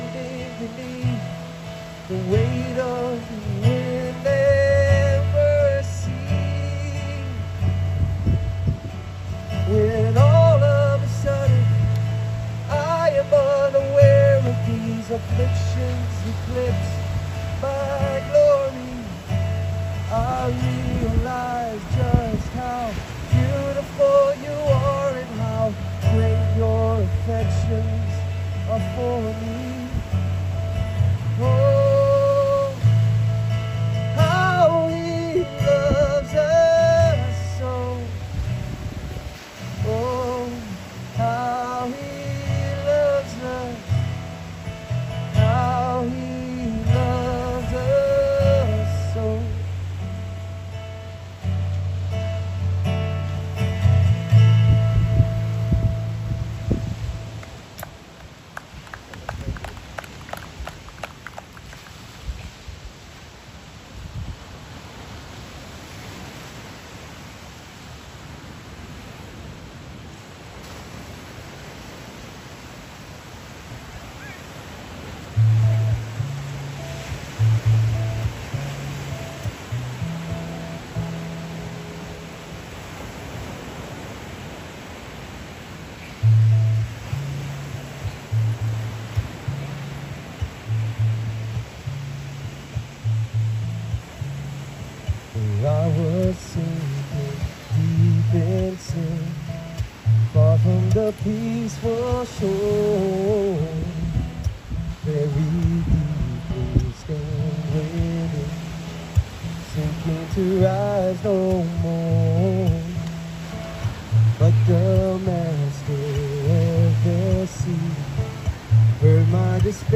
In this sermon, Jesus redefines greatness by revealing that true honor in God’s kingdom comes through humility, service, and childlike faith. As Christ prepares His disciples for His sacrificial mission, He challenges their pride and calls them—and us—to embody a life of selfless love and reconciliation.